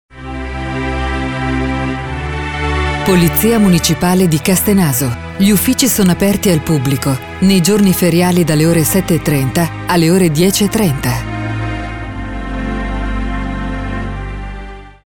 segreterie per risponditori telefonici
Risponditore Polizia Municipale Castenaso